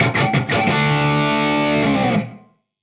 AM_HeroGuitar_85-D01.wav